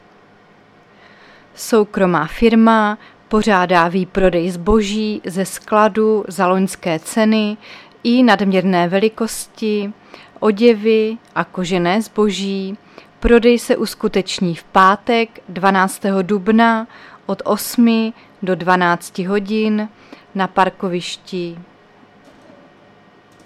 Záznam hlášení místního rozhlasu 11.4.2024
Zařazení: Rozhlas